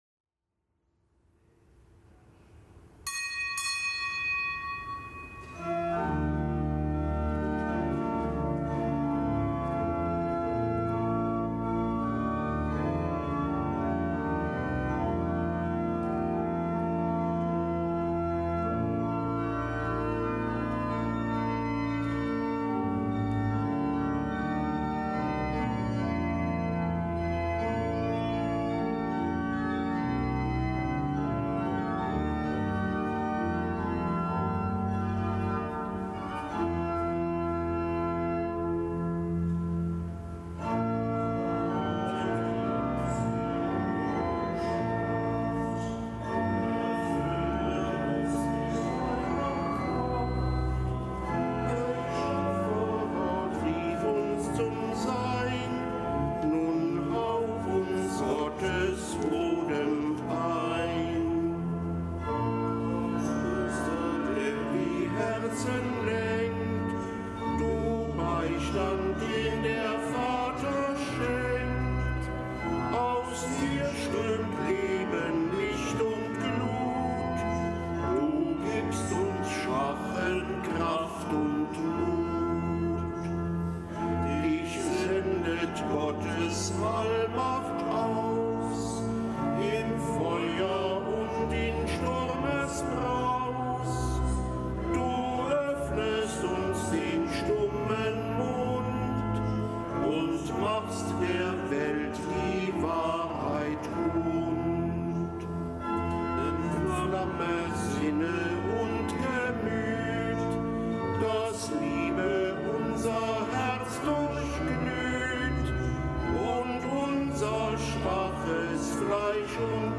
Kapitelsmesse aus dem Kölner Dom am Dienstag der vierten Woche im Jahreskreis, nichtgebotener Gedenktag des Heiligen Rabanus Maurus, Bischof von Mainz (RK).